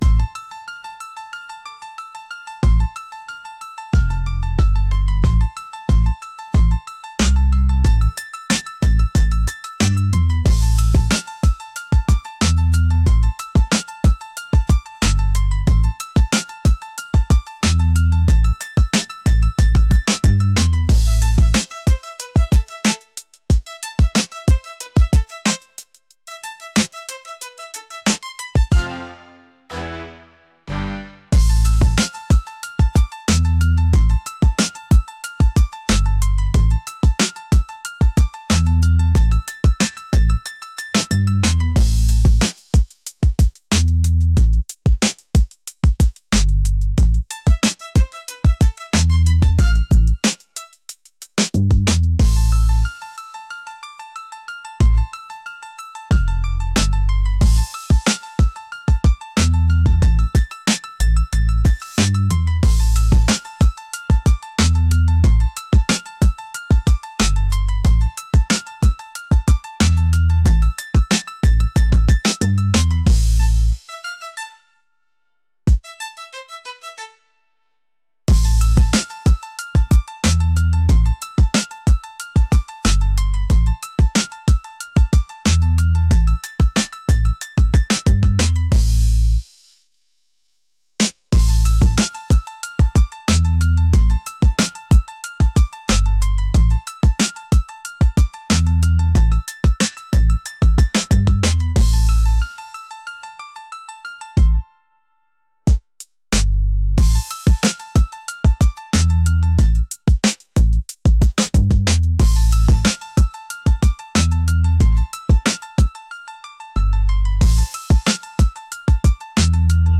rhythmic